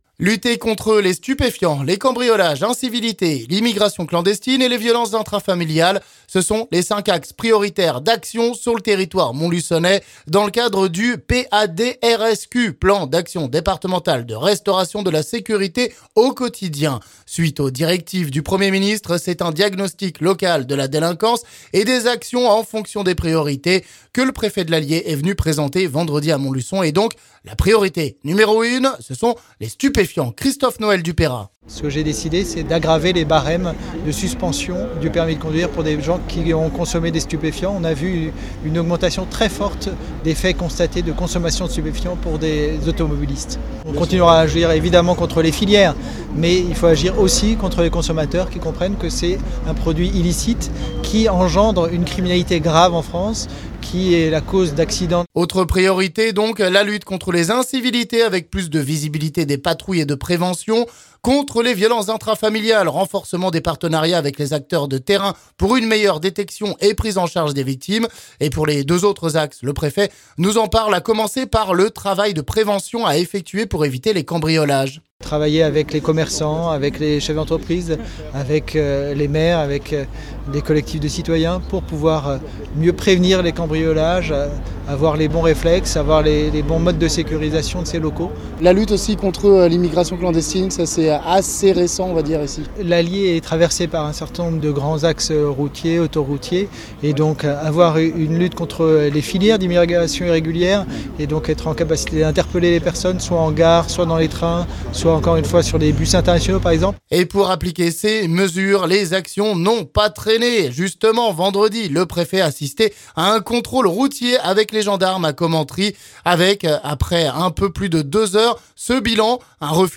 Sujet à écouter ici avec le préfet Christophe Noël du Peyrat...